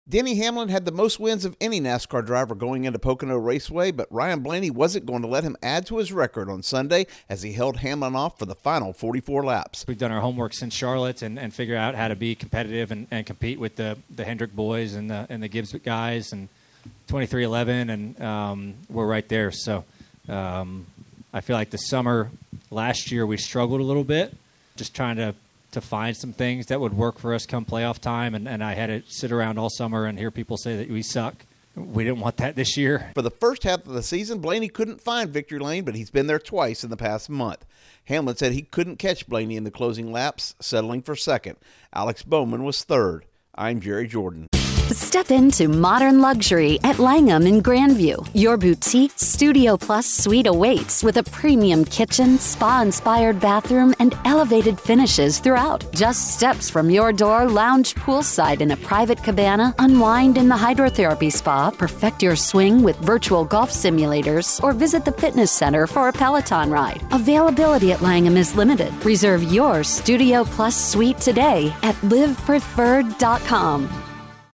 Ryan Blaney takes the checkered flag at Pocono. Correspondent